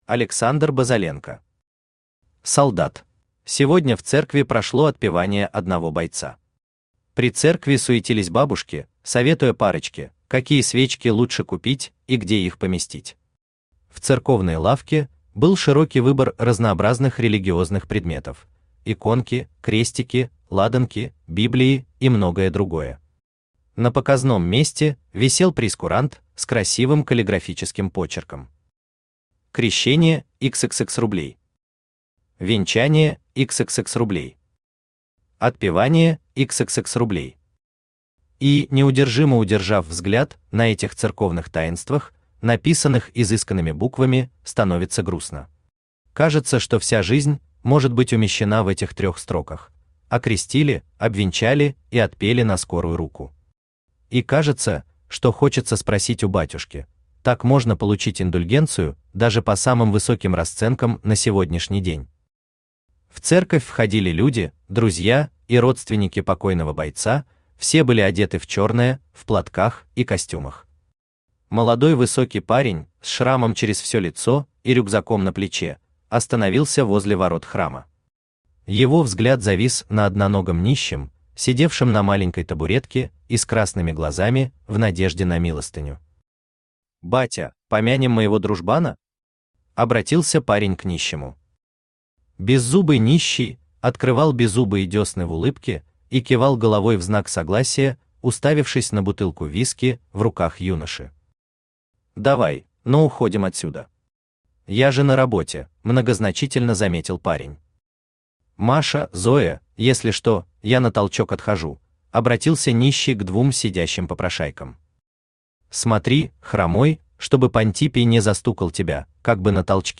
Читает: Авточтец ЛитРес
Аудиокнига «Солдат».